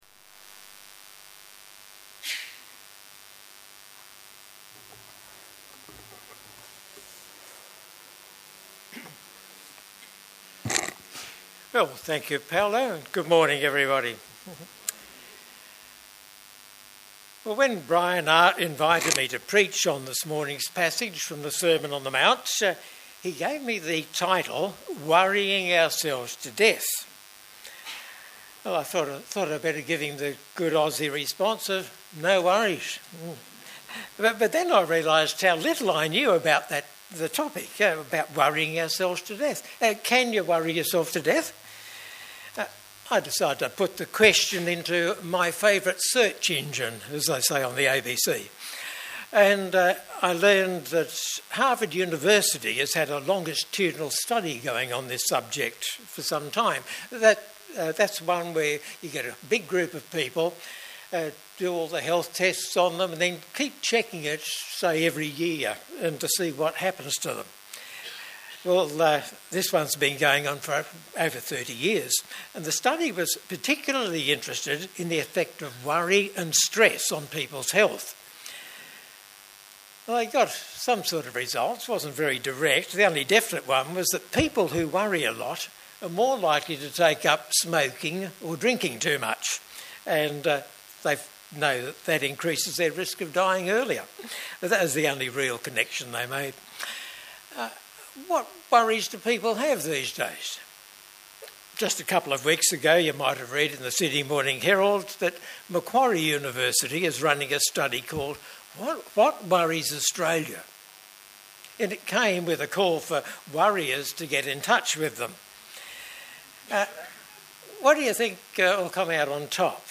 View Sermon details and listen